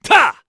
Lusikiel-Vox_Attack4_kr.wav